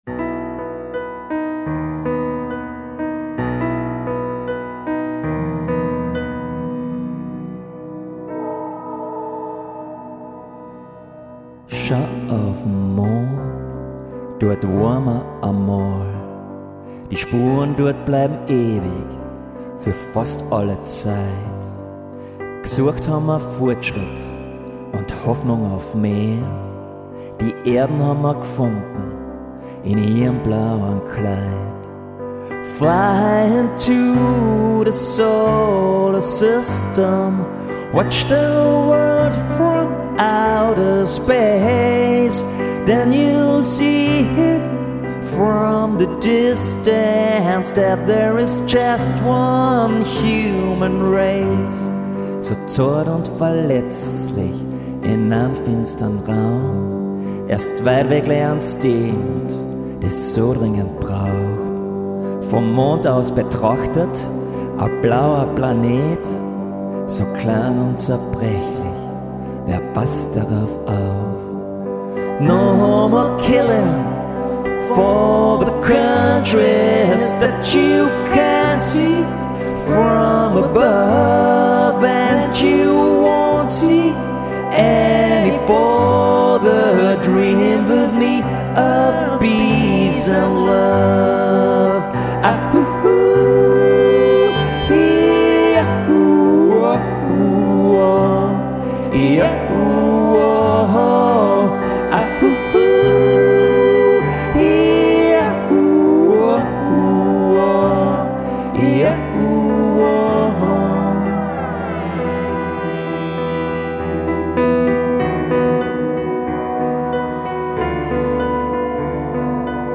vocals, piano, FX, Synthesizer